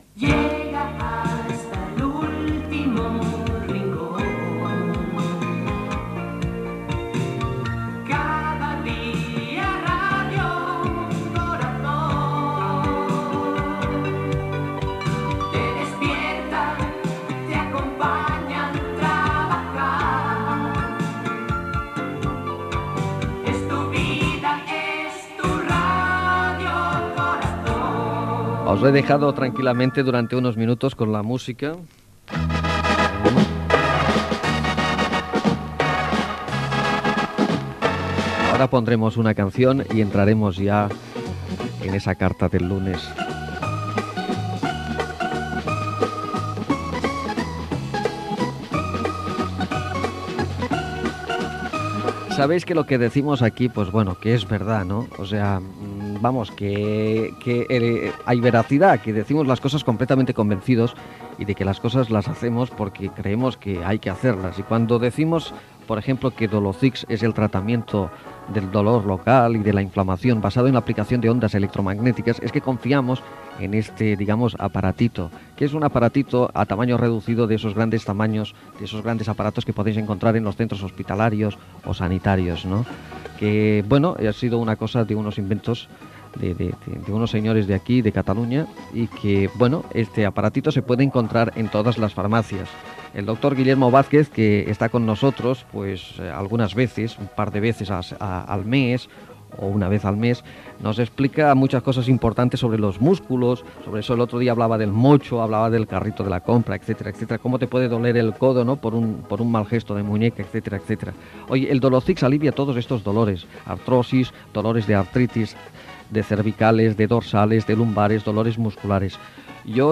Indicatiu cantat de l'emissora, publicitat en directe
Entreteniment
FM